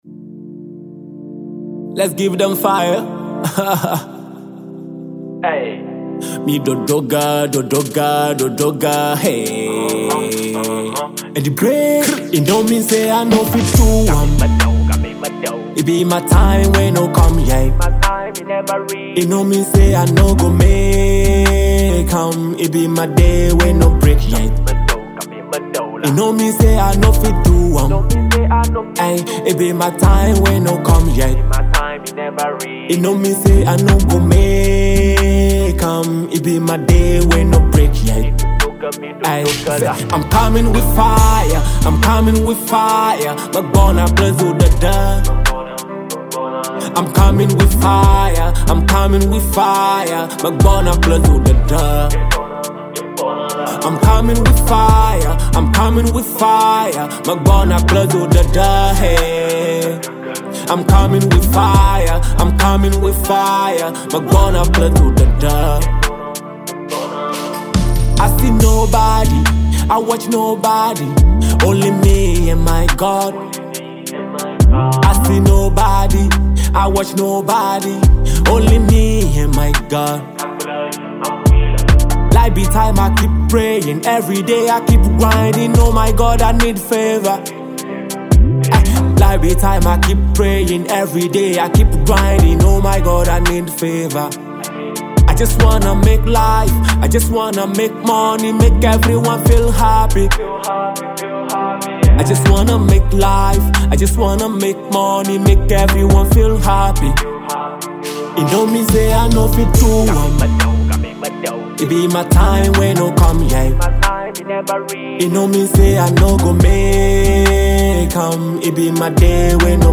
One of the finest Hip-Hop star
motivational banger